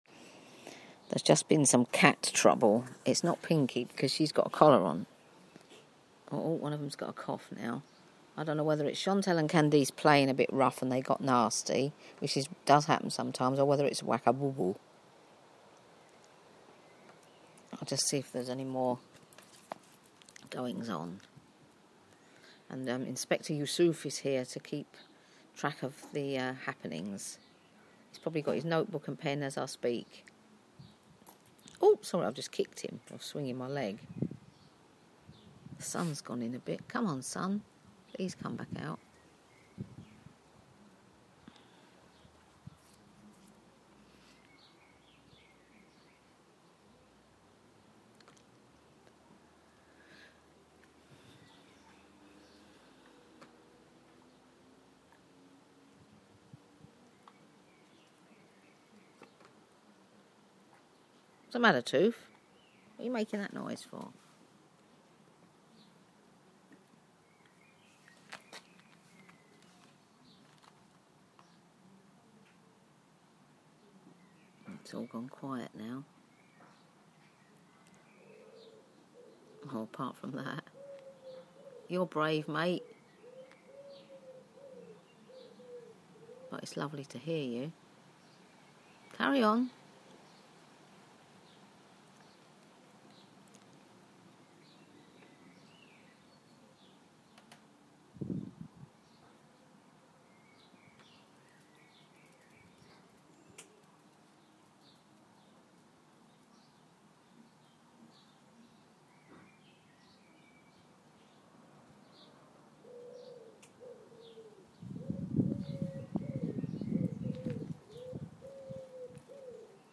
Enjoying some garden sounds